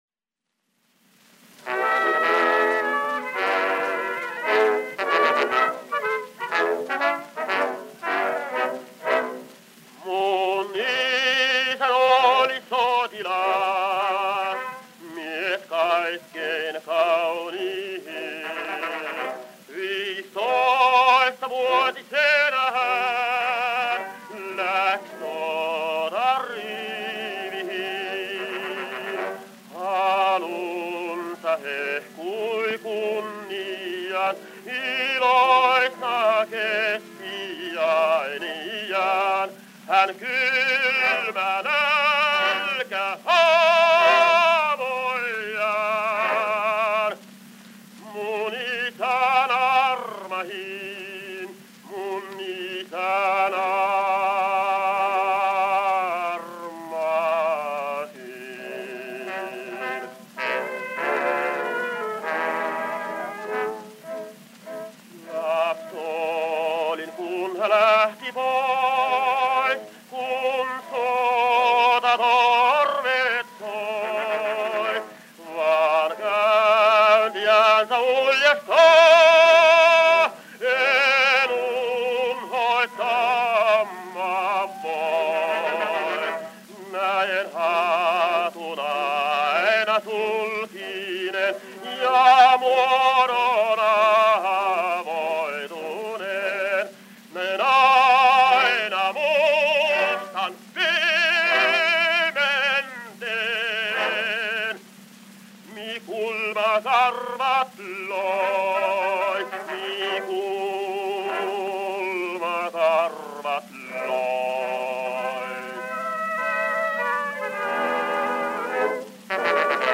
Type folk, Finnish